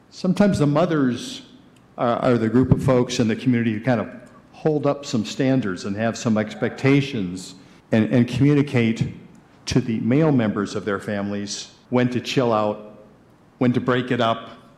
Kalamazoo City Mayor Dave Anderson who was at the scene during the initial investigation, says it was discouraging and depressing to watch the crime scene being searched.